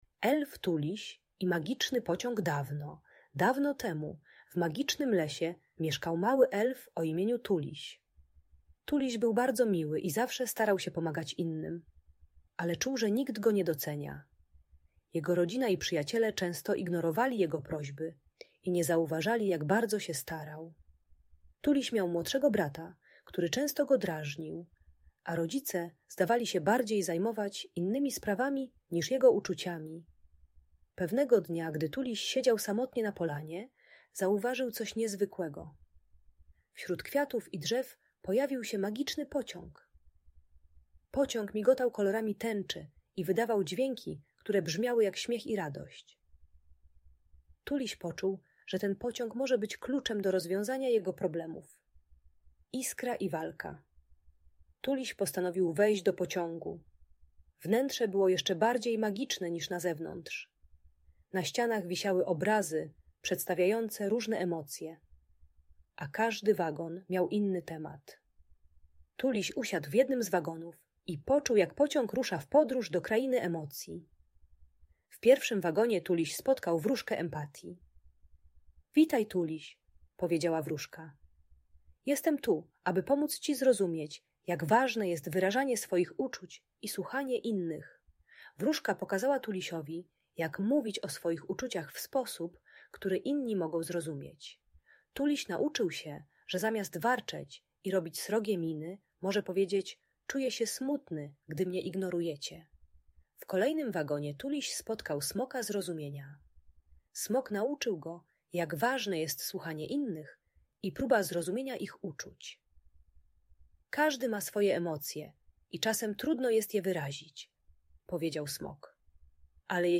Historia Elfa Tulisia i Magicznego Pociągu - Audiobajka dla dzieci